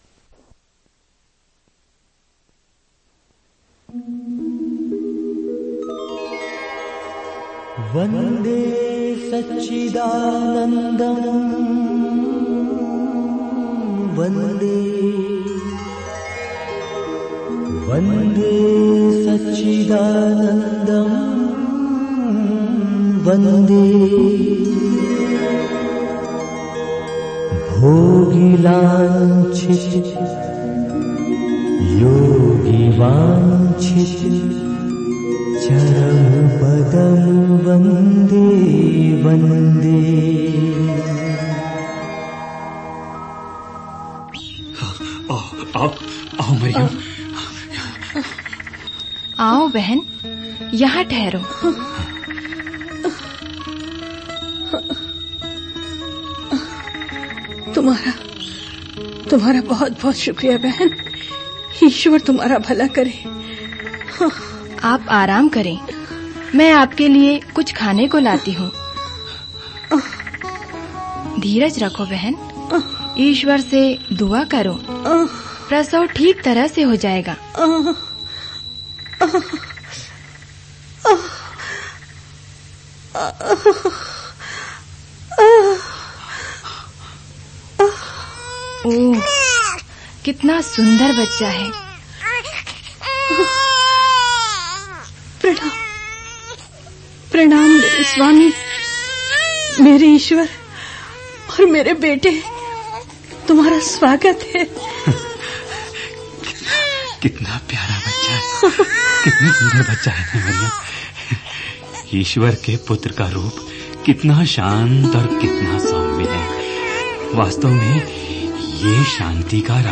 Directory Listing of mp3files/Hindi/Bible Dramas/Dramas/ (Hindi Archive)